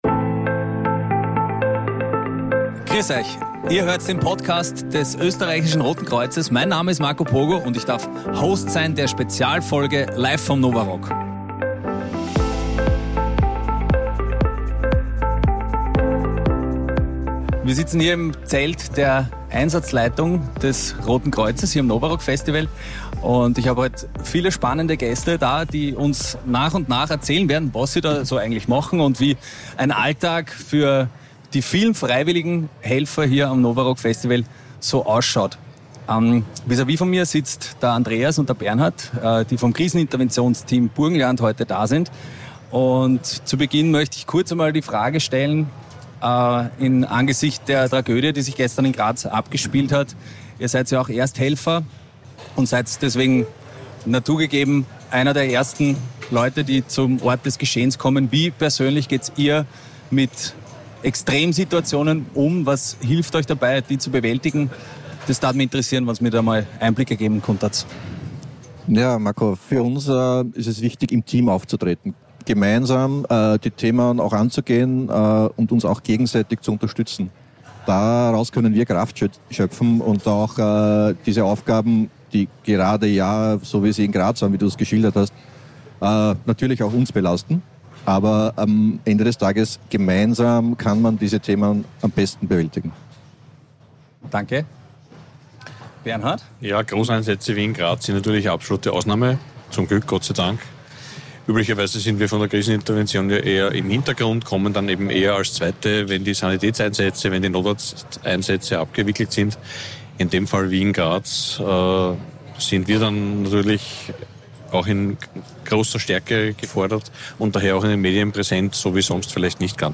LIVE vom NOVA ROCK ~ Österreichisches Rotes Kreuz - der Podcast